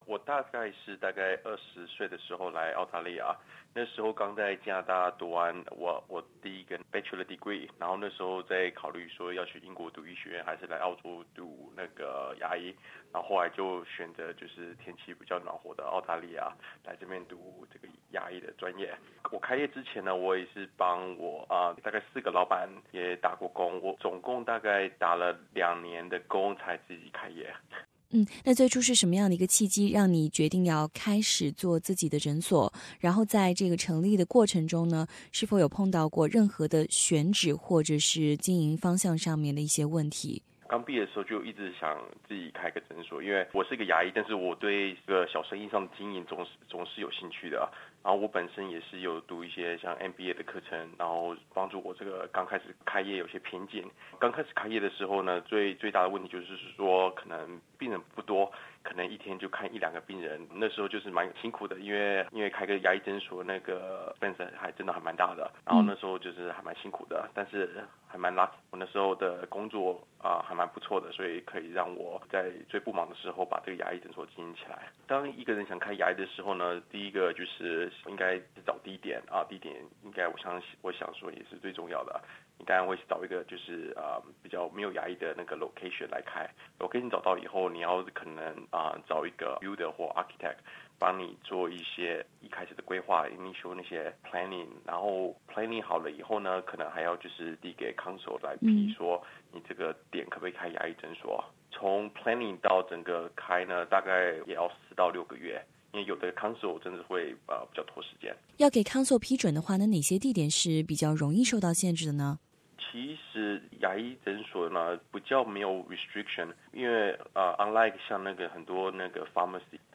首先他介绍了自己来到澳大利亚创立第一家诊所之前的工作背景： 《澳洲华商访谈系列》是SBS普通话节目新推出的一档栏目，每周一期节目，带您探索澳洲本地华裔企业家或小生意者的精彩创业经商故事。